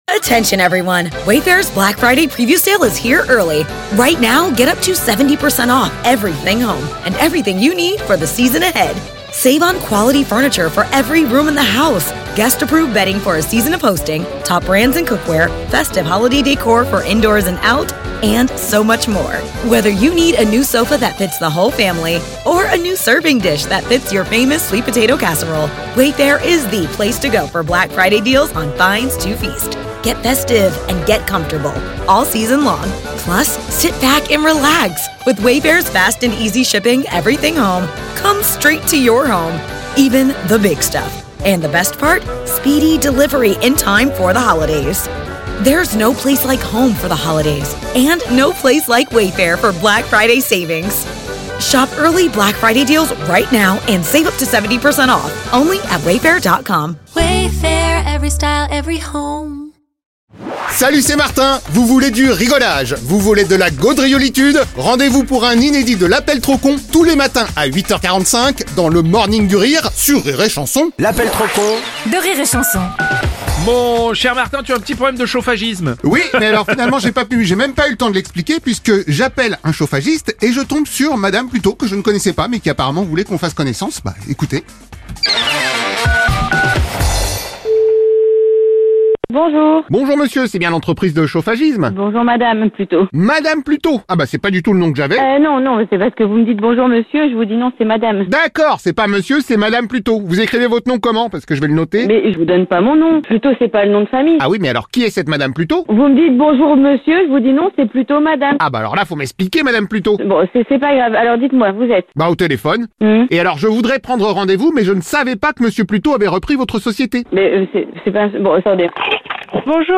Les meilleurs moments de la matinale de Rire & Chansons de Bruno Roblès